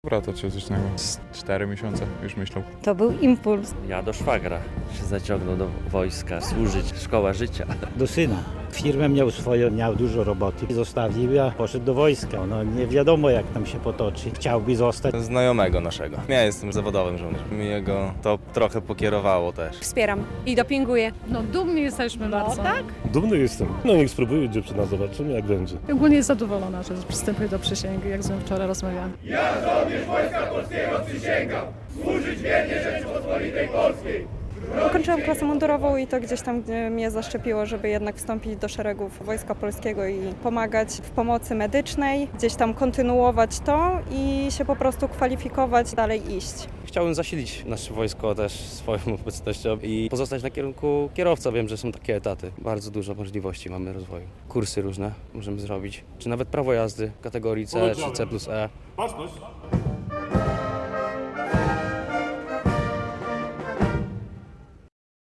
To czwarty w tym roku turnus takiego 27-dniowego szkolenia i trzecia przysięga na Starym Rynku w Łomży.